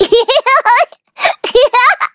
Taily-Vox_Happy3.wav